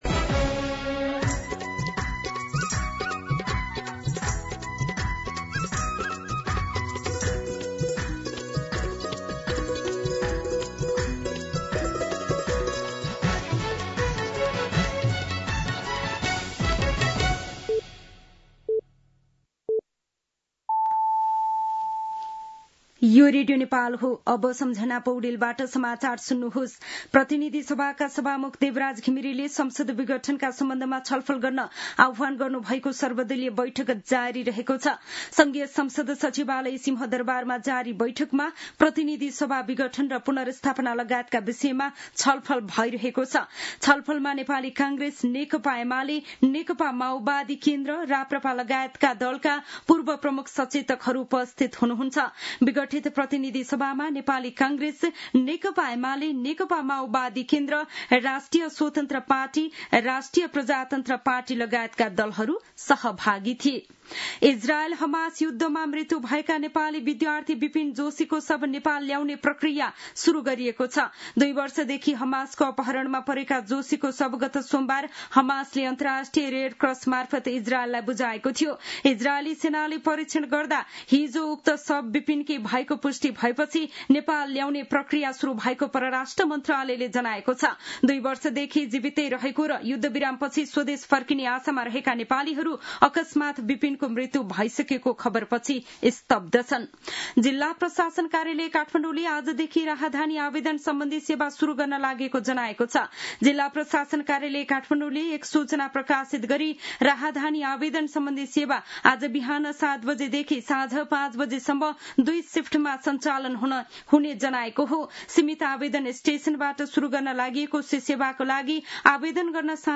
दिउँसो १ बजेको नेपाली समाचार : २९ असोज , २०८२
1pm-News-06-29.mp3